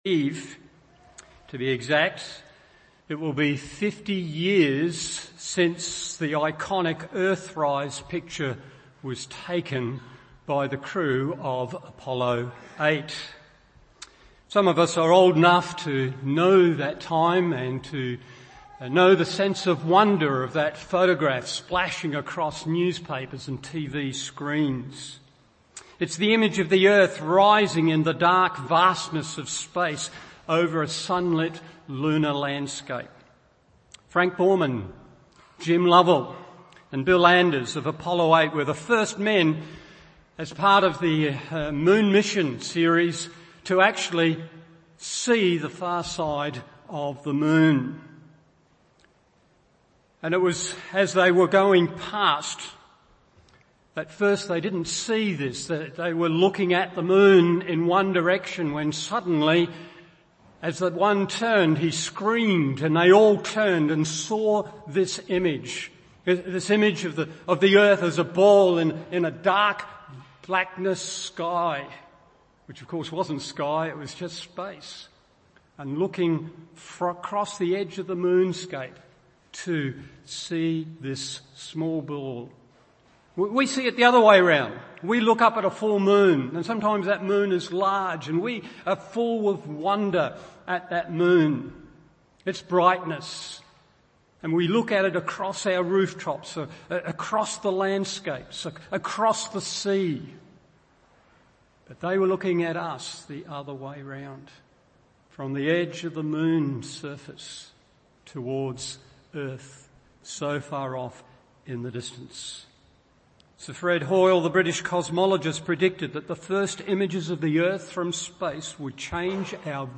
Morning Service Genesis 1:2-31 1. The Process God Used 2. The Progress God Made 3. The Pleasure God Affirmed…